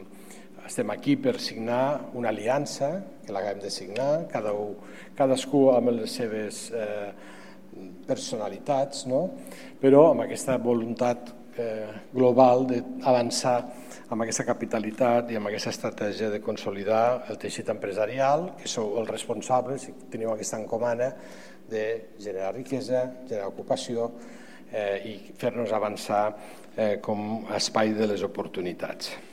Tall de veu del paer en cap, Fèlix Larrosa, sobre l'aliança de la Paeria i entitats empresarials per incentivar el desenvolupament econòmic i social de la ciutat (298.4 KB) Tall de veu de la tinenta d'alcalde Carme Valls sobre l'aliança de la Paeria i entitats empresarials per incentivar el desenvolupament econòmic i social de la ciutat (358.8 KB)